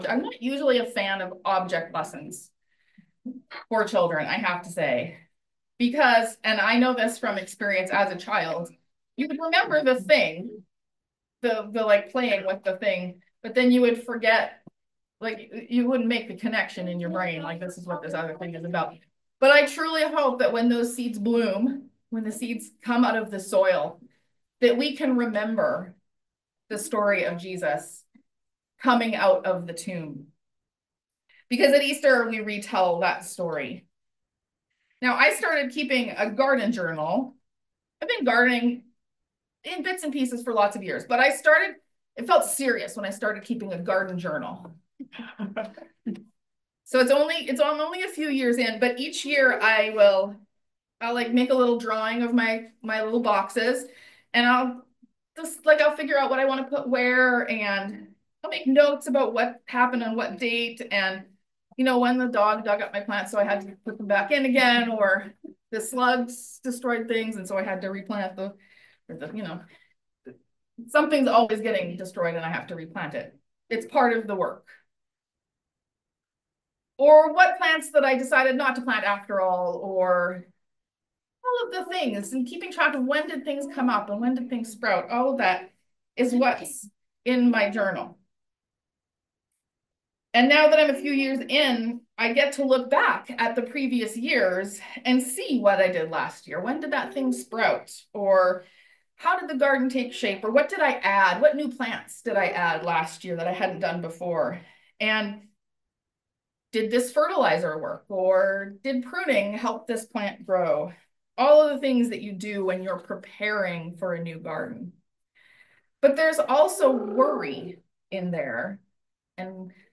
Sermon: I started keeping a garden journal a few years ago.